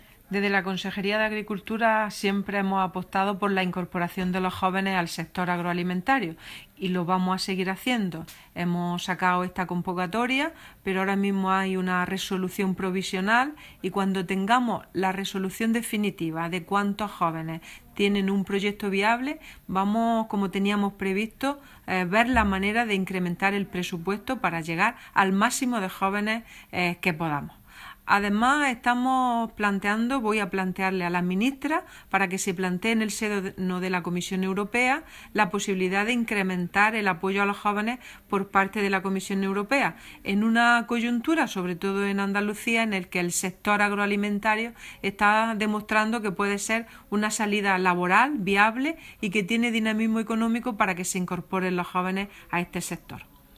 Declaraciones de Carmen Ortiz sobre ayudas para la incorporación de jóvenes a la actividad agraria